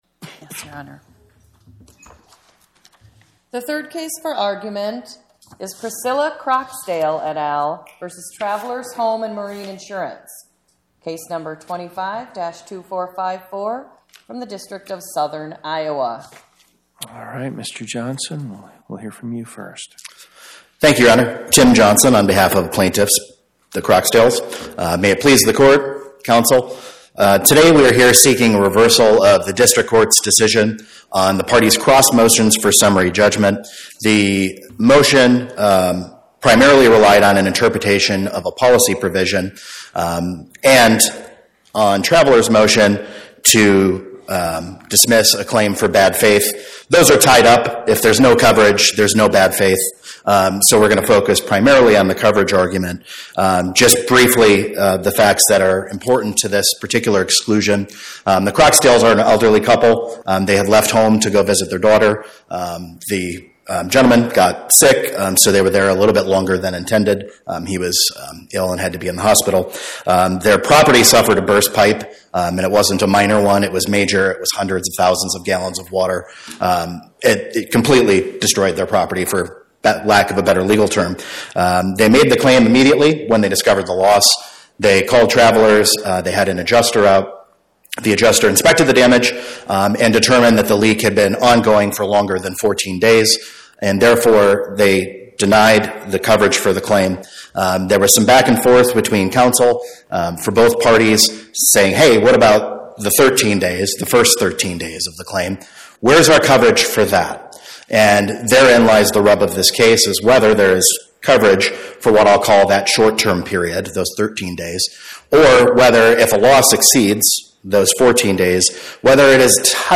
Podcast: Oral Arguments from the Eighth Circuit U.S. Court of Appeals Published On: Tue Mar 17 2026 Description: Oral argument argued before the Eighth Circuit U.S. Court of Appeals on or about 03/17/2026